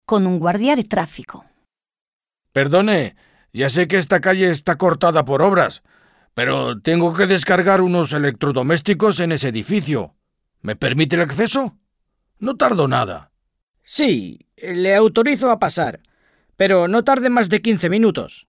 Dialogue - Con un guardia de tráfico